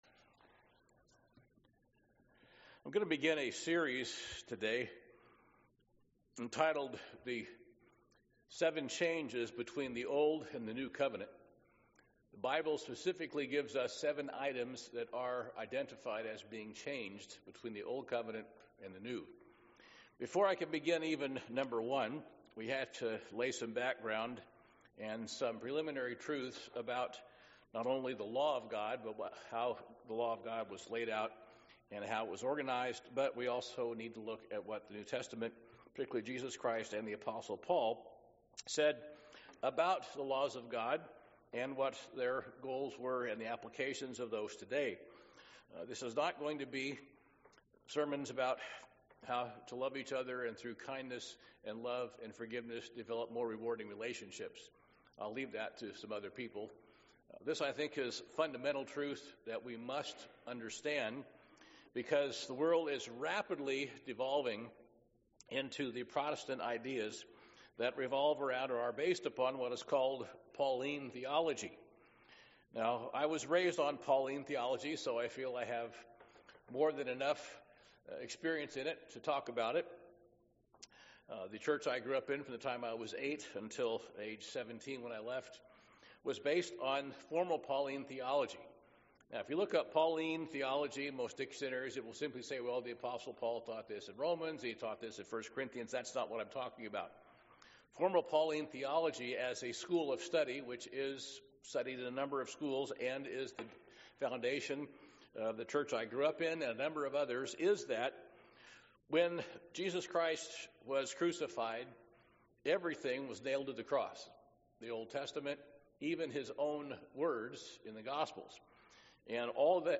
Sermons
Given in Portland, OR